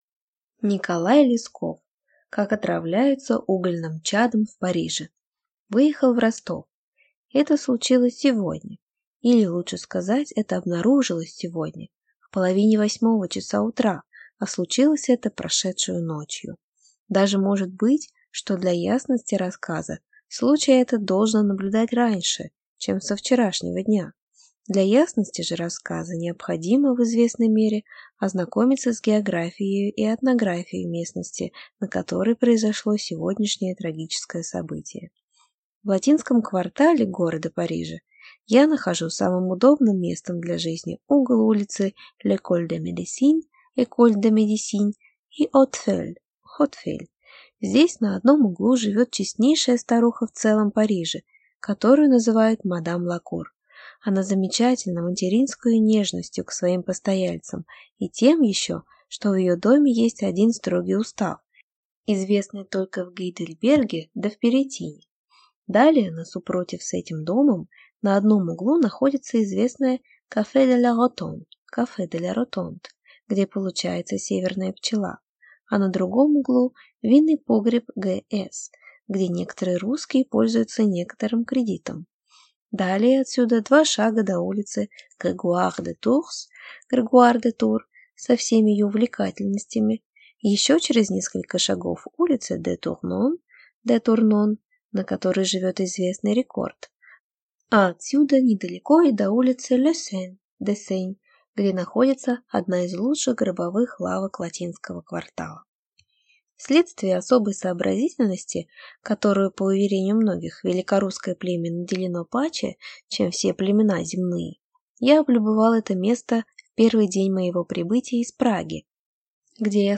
Аудиокнига Как отравляются угольным чадом в Париже | Библиотека аудиокниг